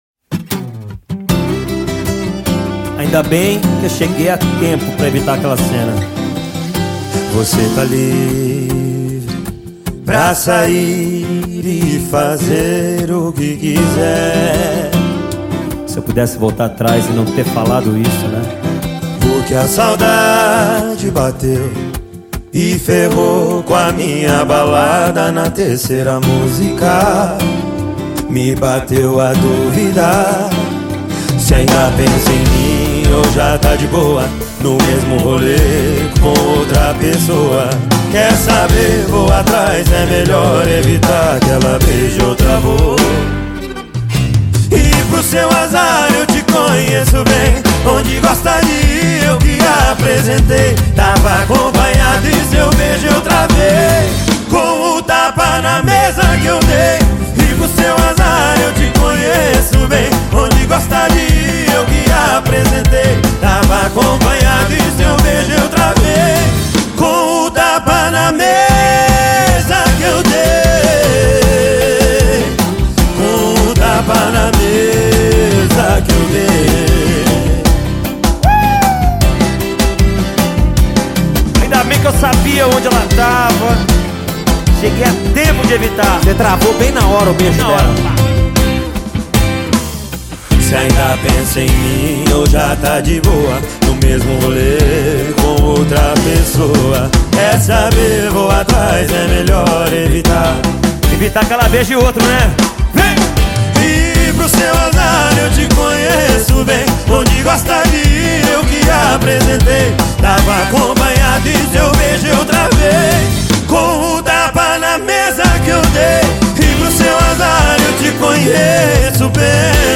musical group and talented singers